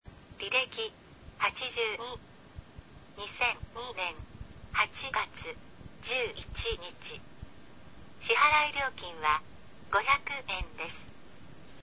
このように西暦も案内される。音もきれいであった。